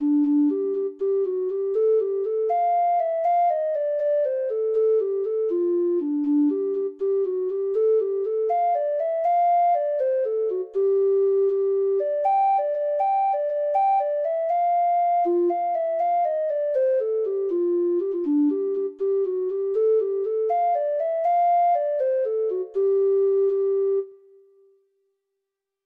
Free Sheet music for Treble Clef Instrument
Irish